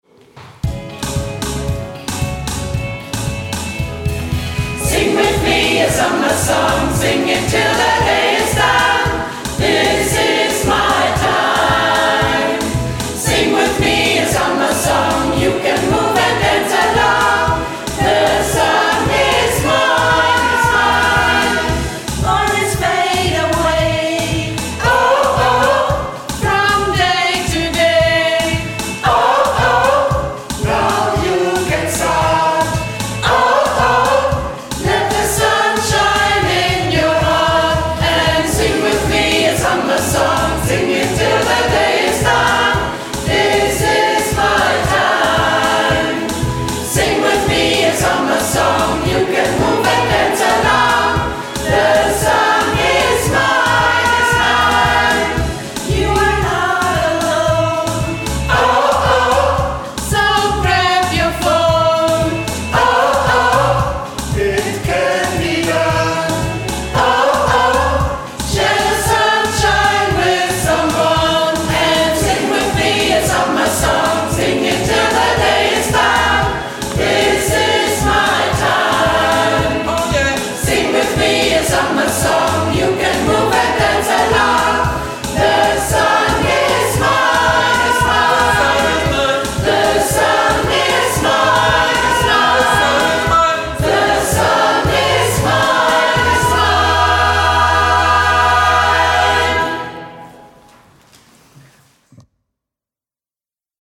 Workshop 2025
Aufnahmen von den Proben